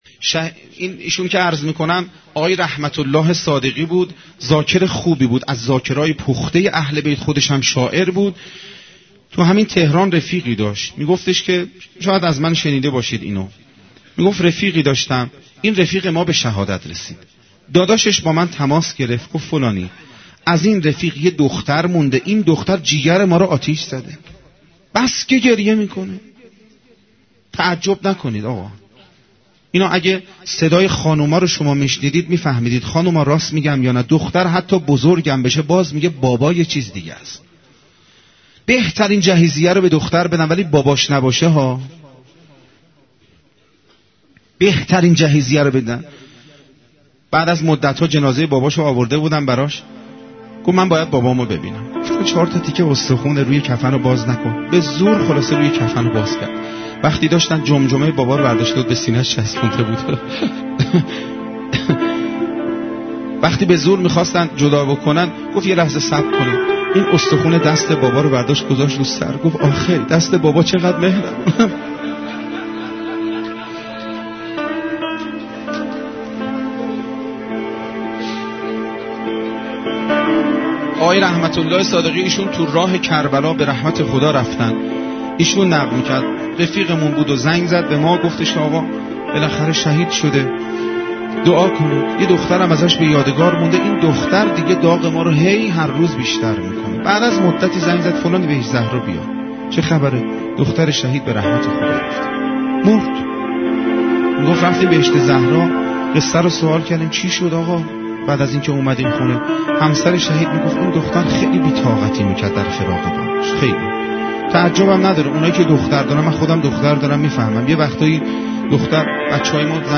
ravayatgari196.mp3